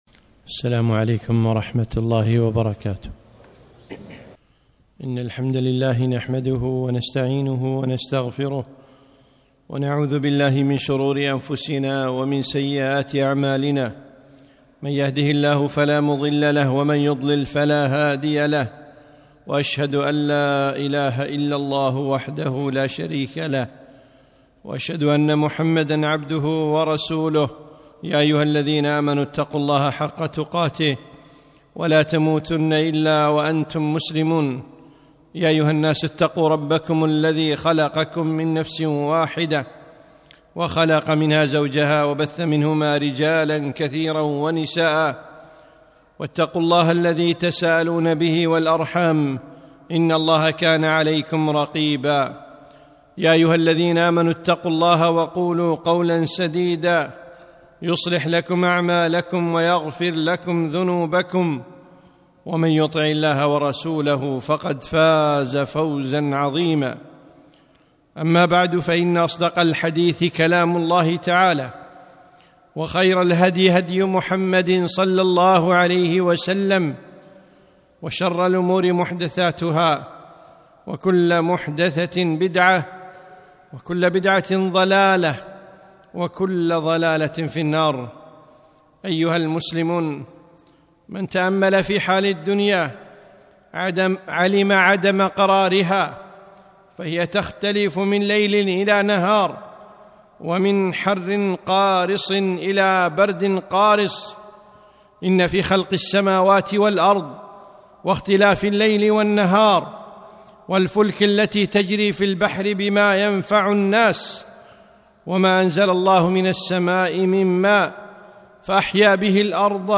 خطبة - من أحكام الشتاء